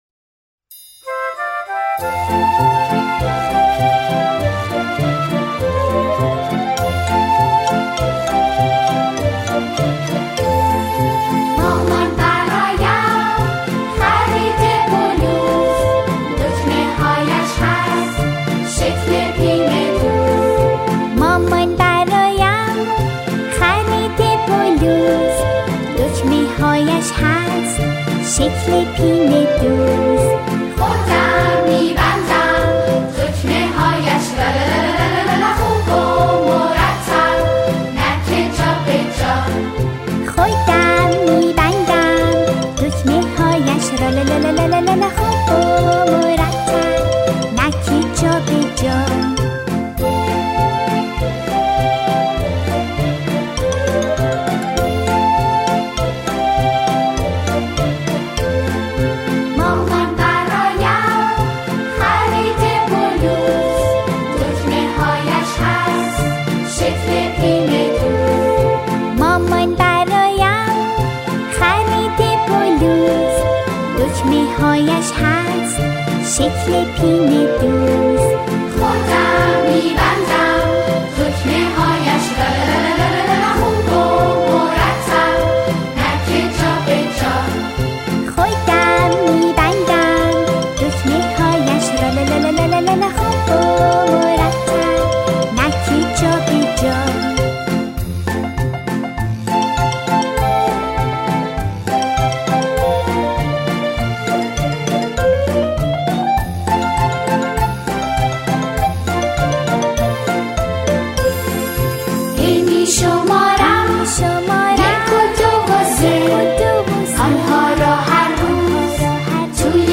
دسته : نیوایج